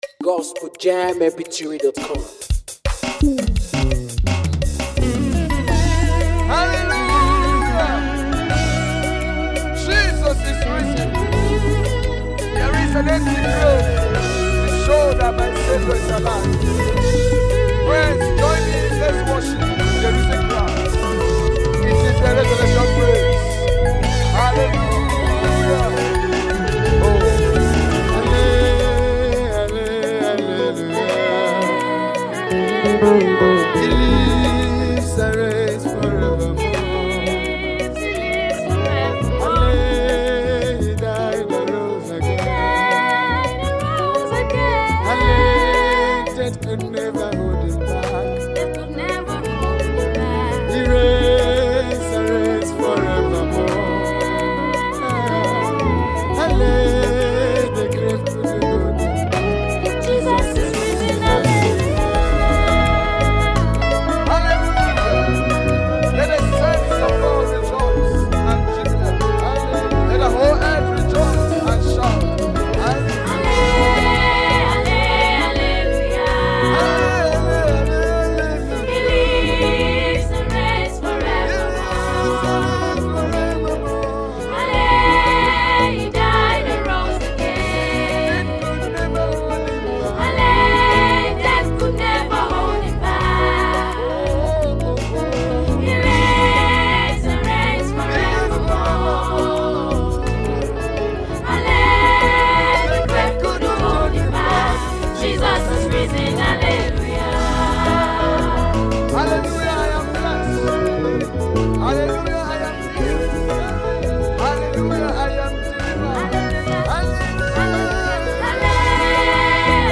A gospel song writer and artiste.